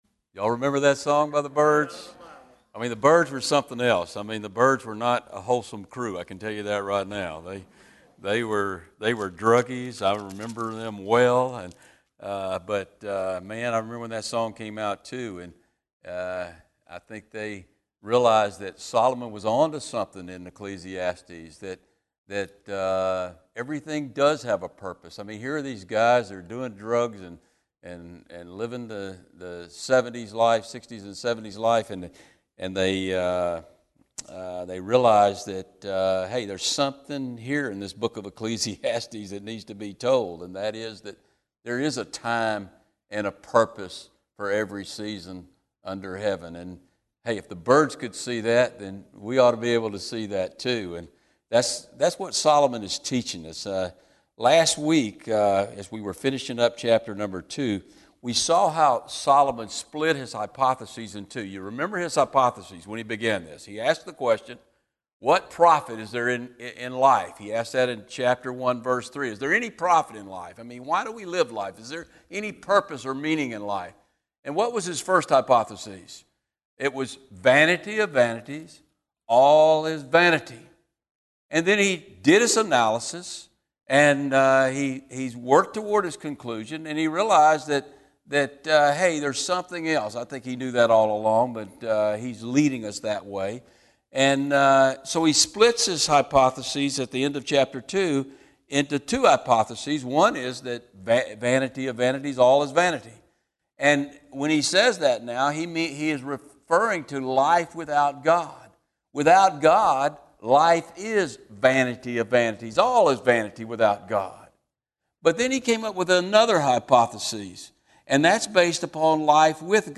These teachings on Ecclesiastes are from Wednesday evening service.